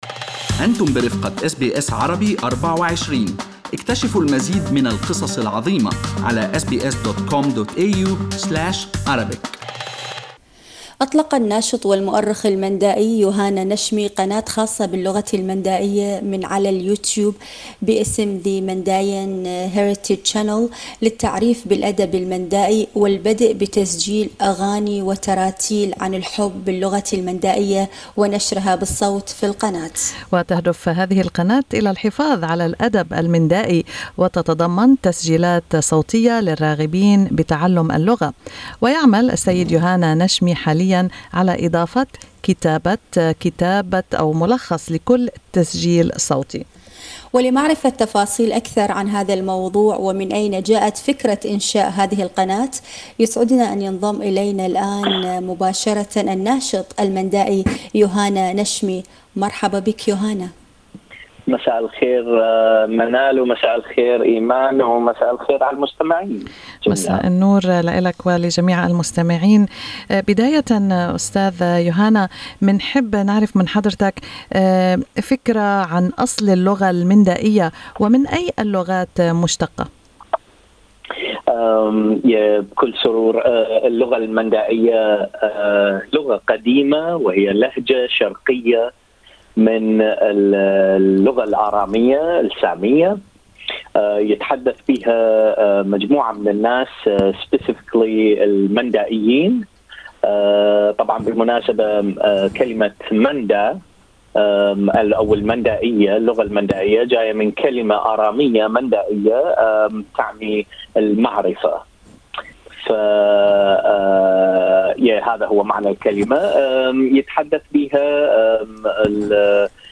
في حديثِ مع برنامج أستراليا اليوم